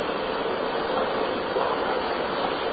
That seems to be the question I was asked when I did an EVP session at a nursing home.